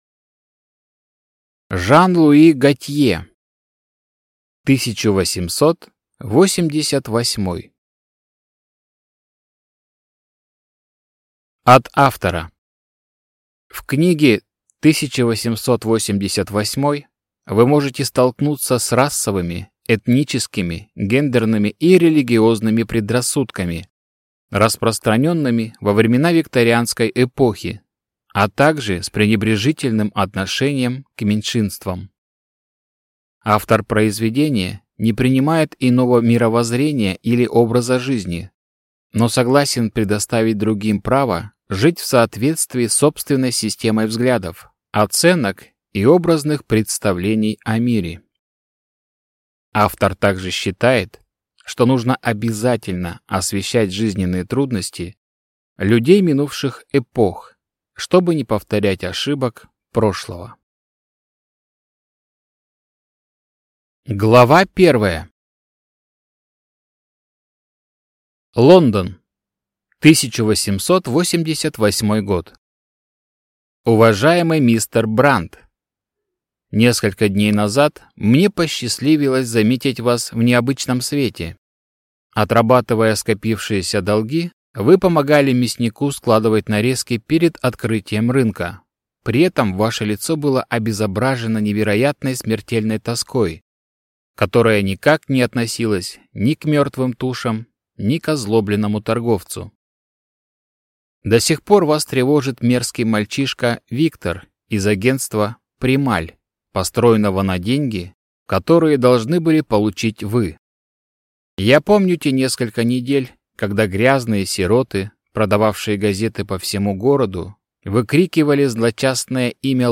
Аудиокнига 1888 | Библиотека аудиокниг